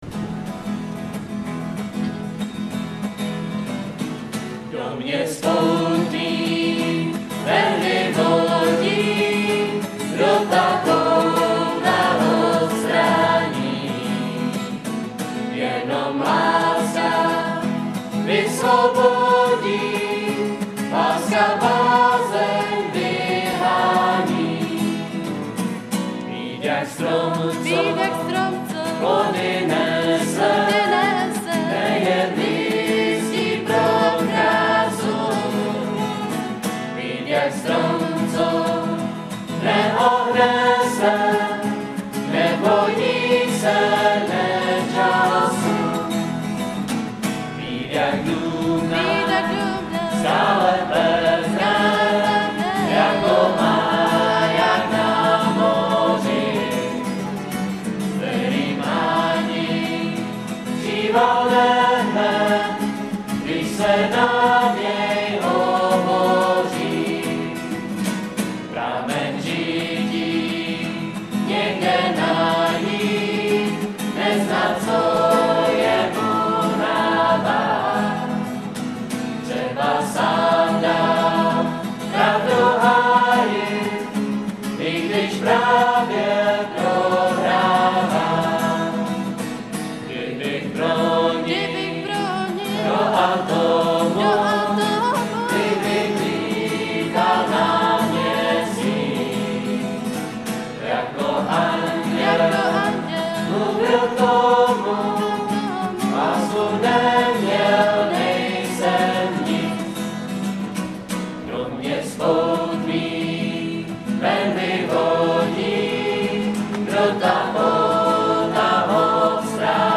Klíčová slova: křesťanská hudba, písně, mládež, Brno,